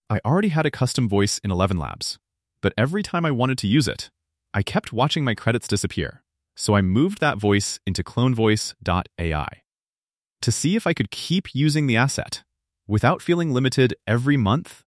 Now try the same message with proper pauses:
Same idea. Same voice. Much better pacing.